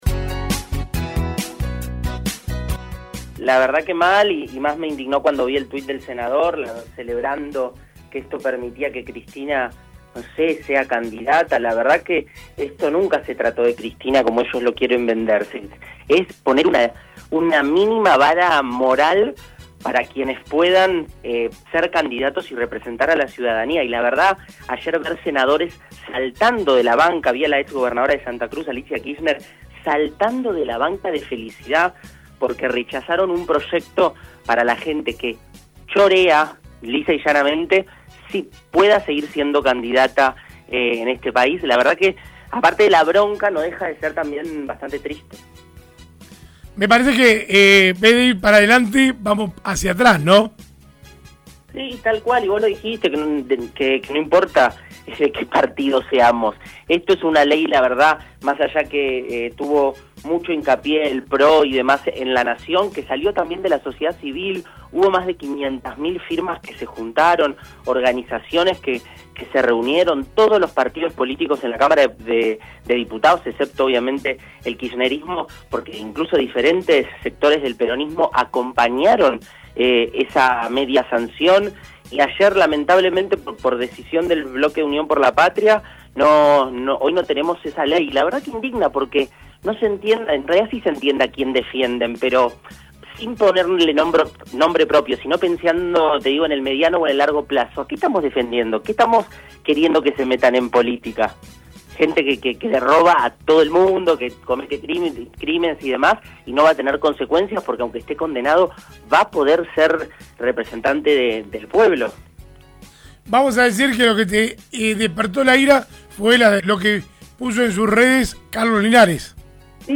El diputado provincial de Despierta Chubut, en diálogo con LA MAÑANA DE HOY, estableció su descontento con la votación en el Senado y la reacción de los kirchneristas ante tal número: